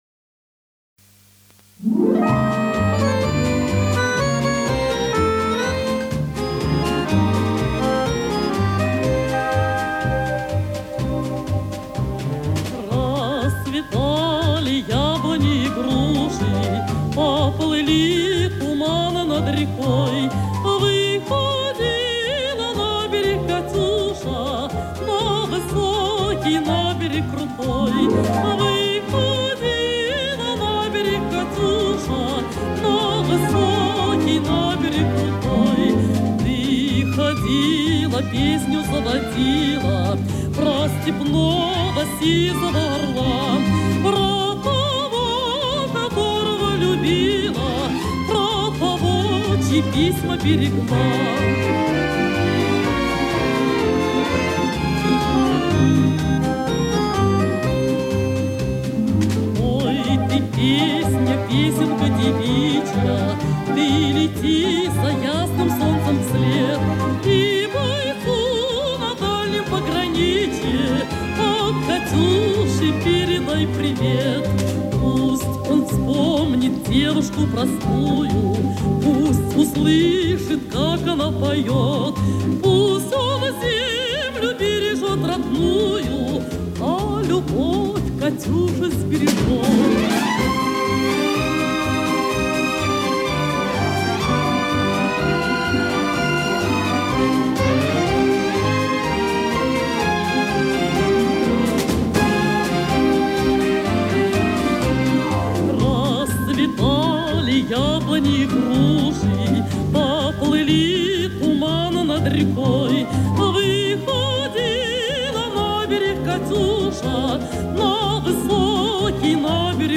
Еще одна запись ленинградской певицы
Источник Радио